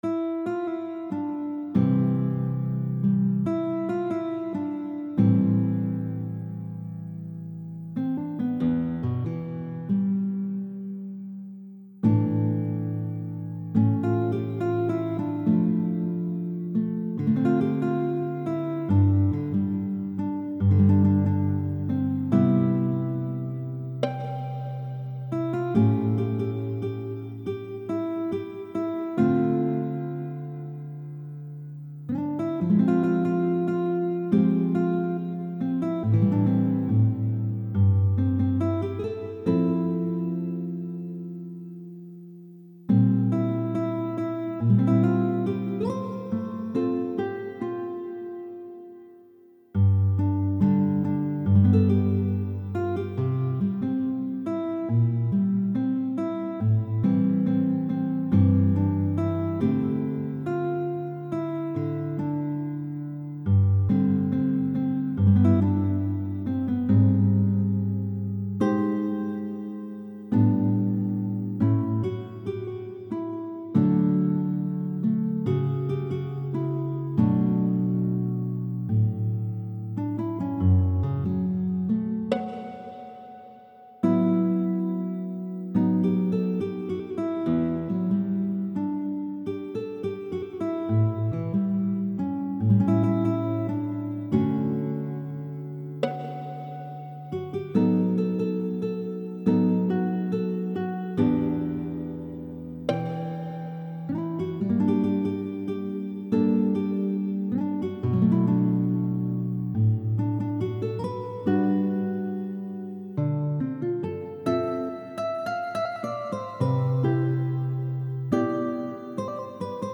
solo guitar chitarra sola + solo integrale